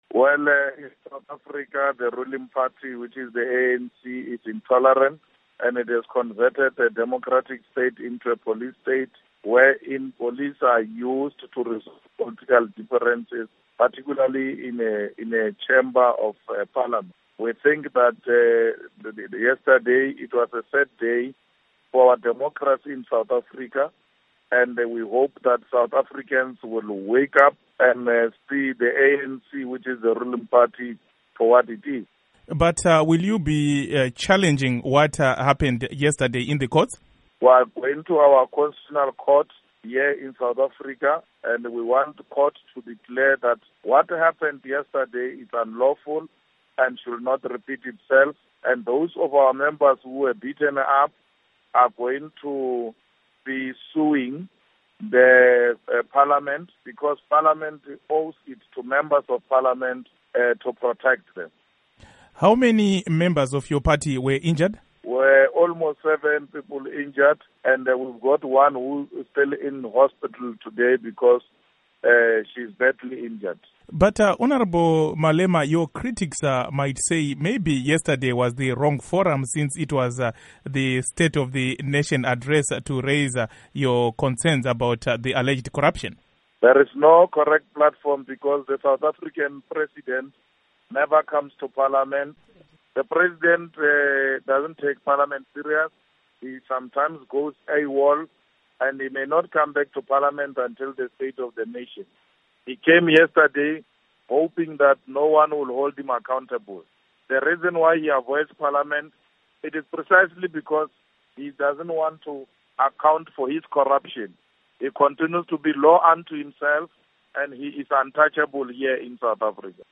Interview With Julius Malema